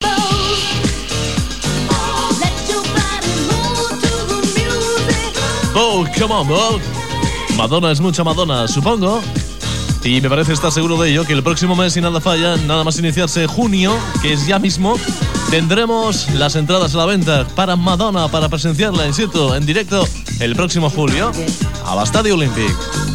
5f16d900ba81dfdc528ecb724fca5efcad7db113.mp3 Títol Radio Club 25 Emissora Radio Club 25 Titularitat Privada local Descripció Anunci del concert de Madonna a l'Estadi Olímpic de Barcelona.